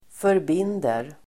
Uttal: [förb'in:der]